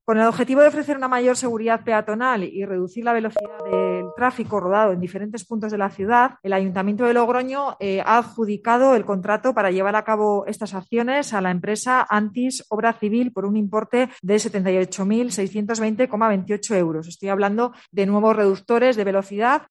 Eva Tobías, portavoz del Ayuntamiento de Logroño